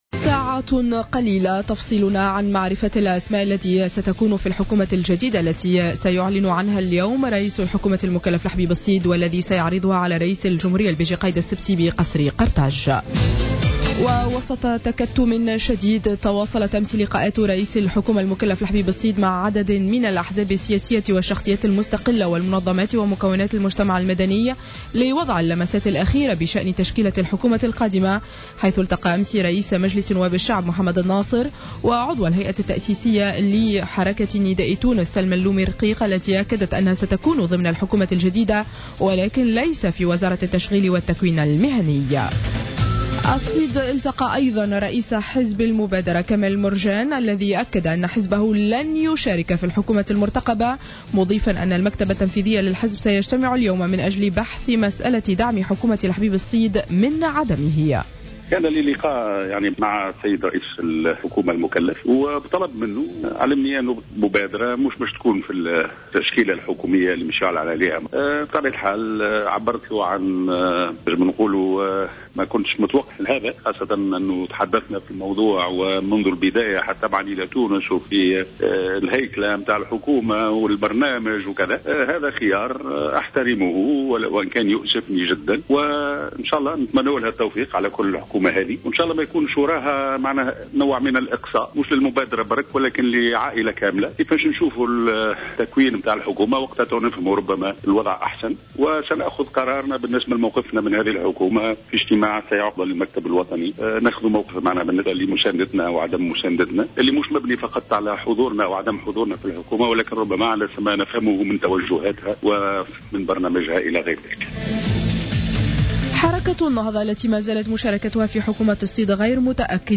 نشرة اخبار السابعة صباحا ليوم الإثنين 02-02-15